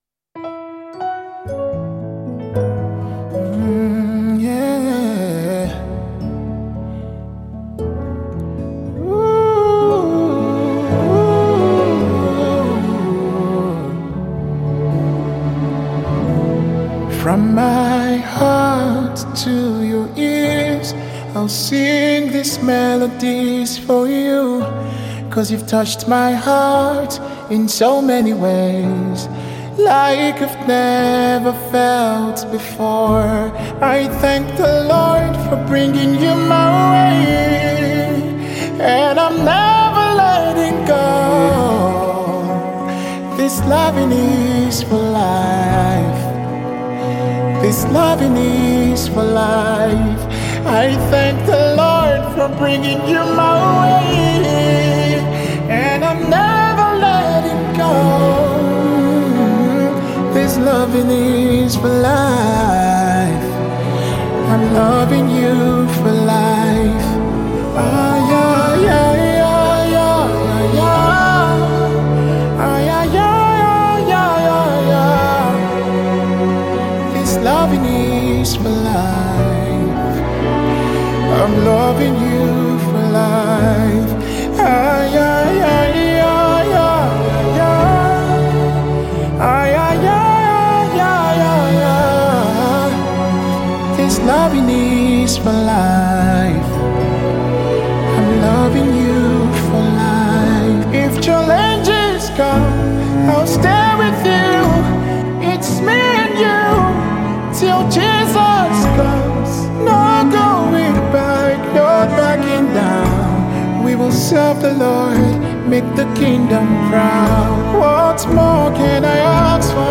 Talented Nigerian Gospel artist